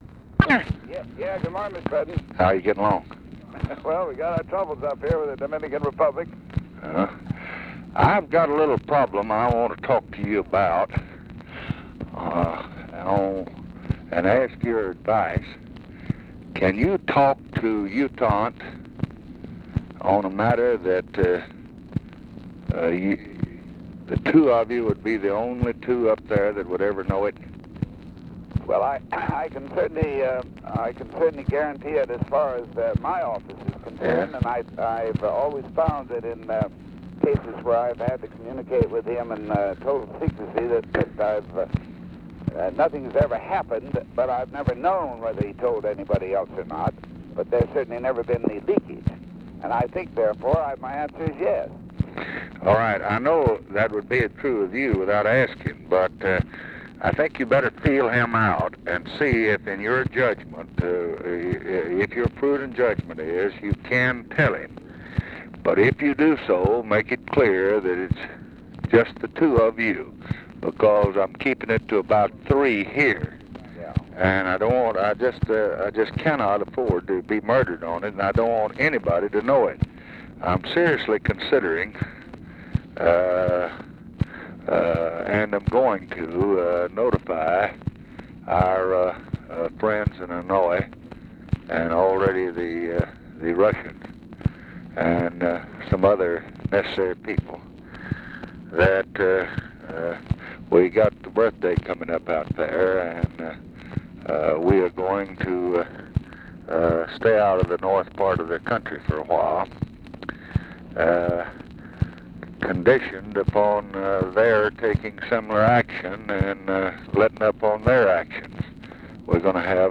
Conversation with ADLAI STEVENSON, May 12, 1965
Secret White House Tapes